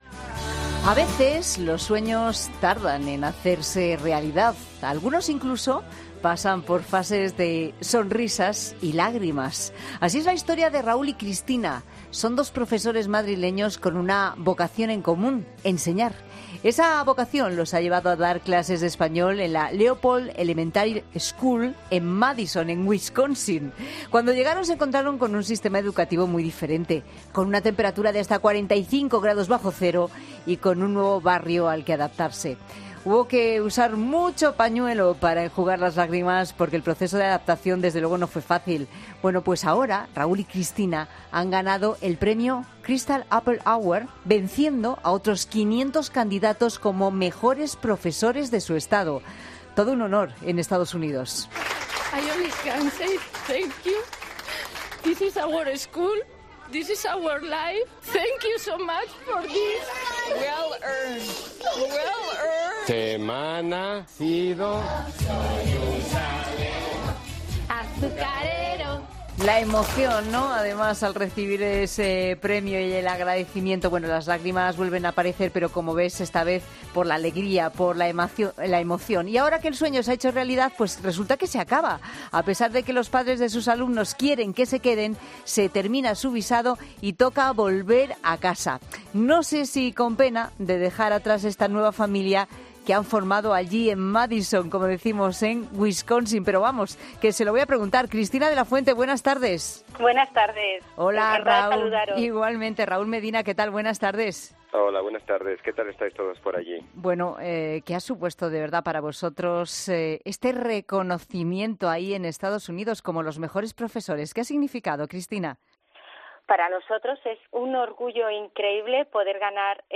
han estado en 'La Tarde' de COPE para contar más detalles de cómo fue su adaptación